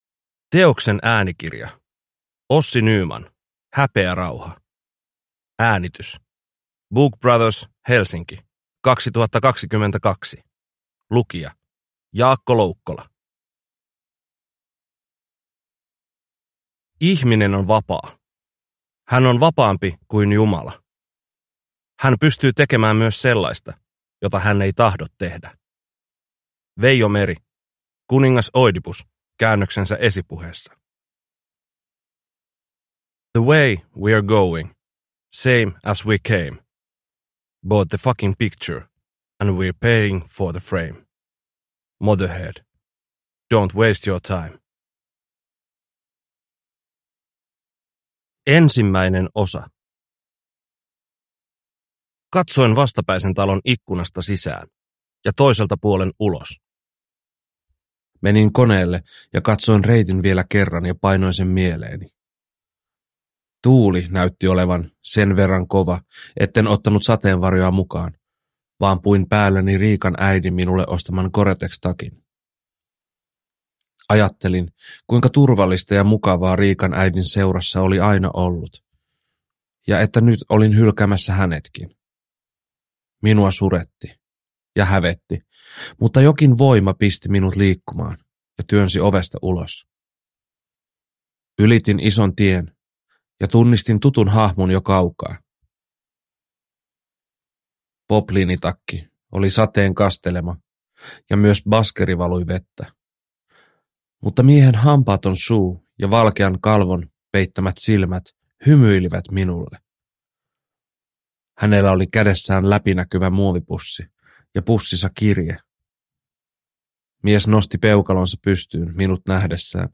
Häpeärauha – Ljudbok – Laddas ner